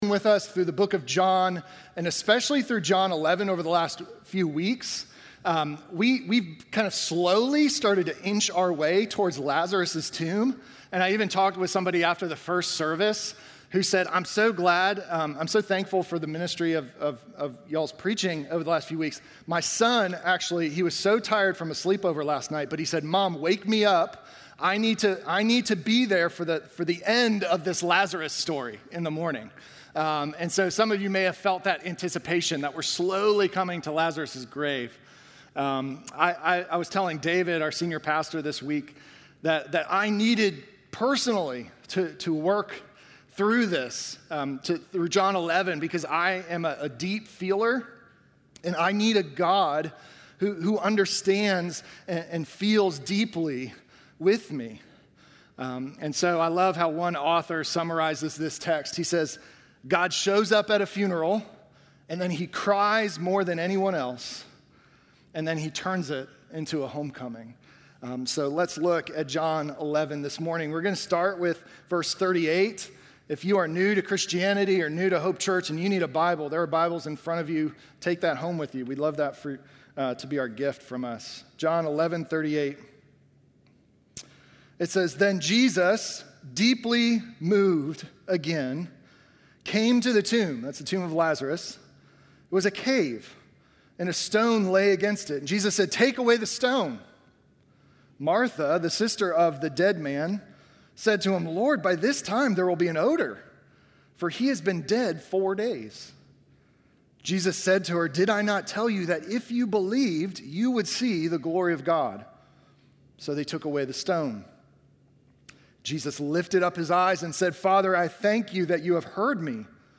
Sermon from November 23